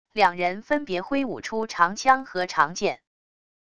两人分别挥舞出长枪和长剑wav音频